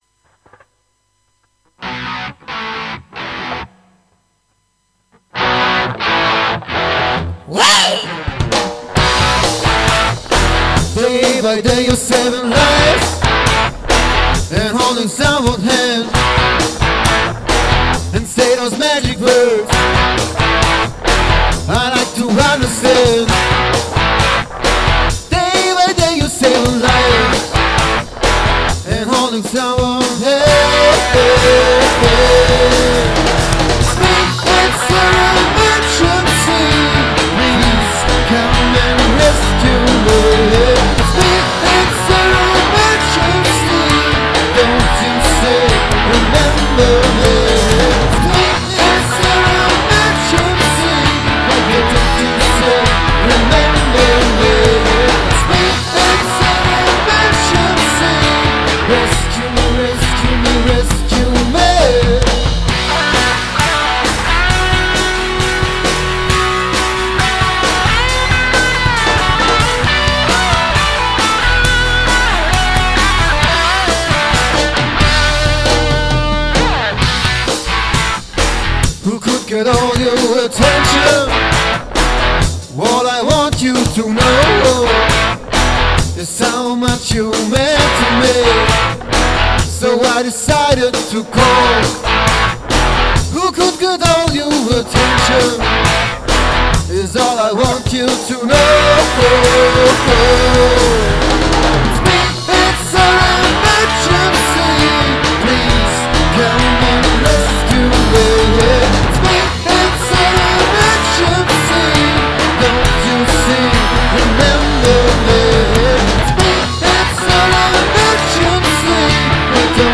Gitarre
Schlagzeug
Bass/Gesang